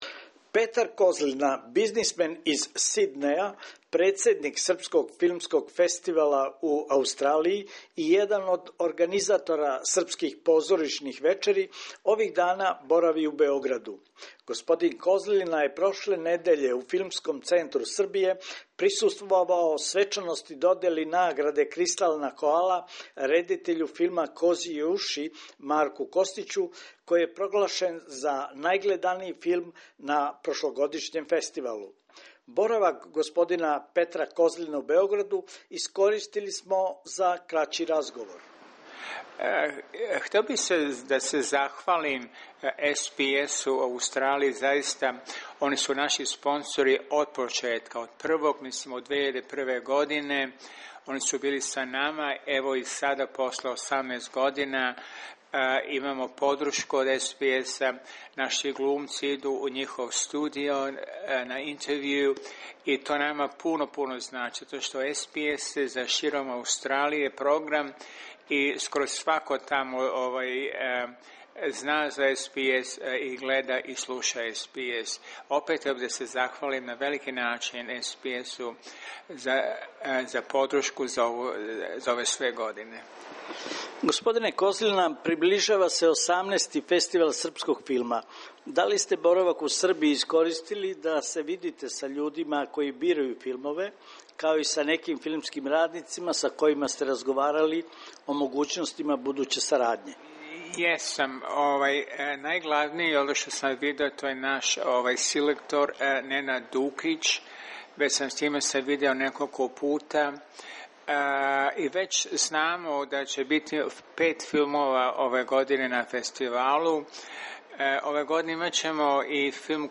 како је рекао у интервјуу за СБС